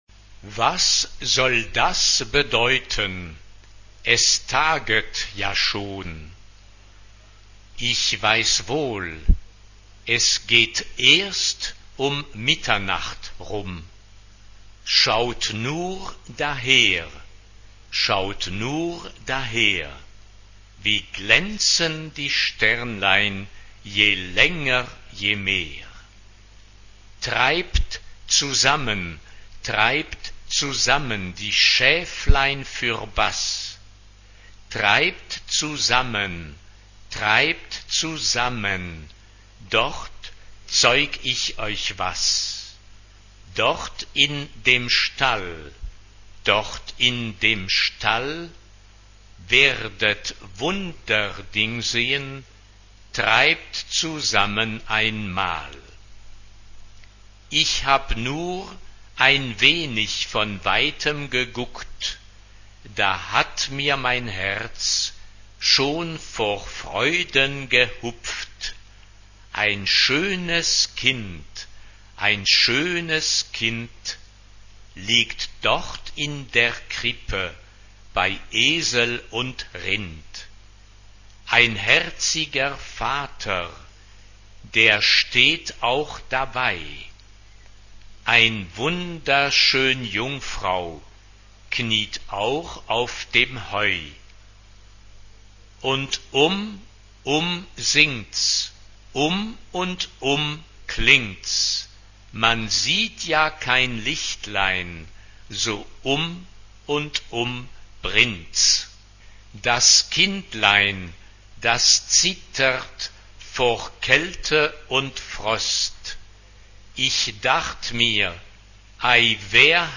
Tonality: F major